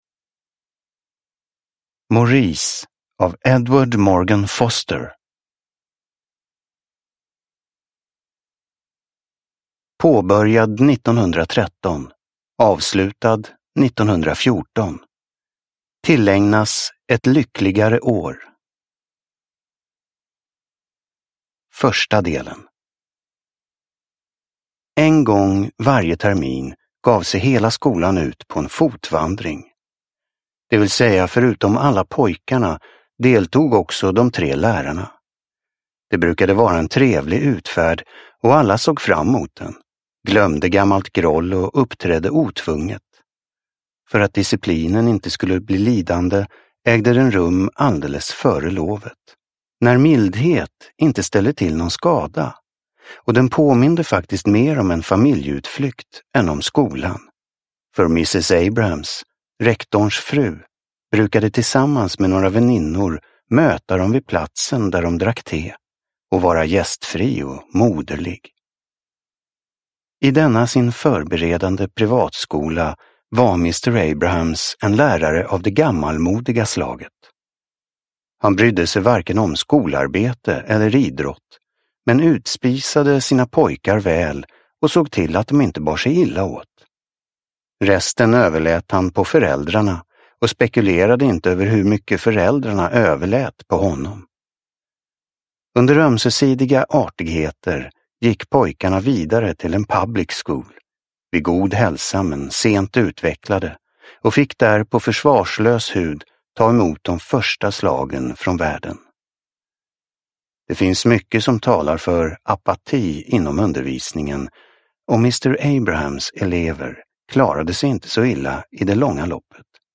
Maurice – Ljudbok – Laddas ner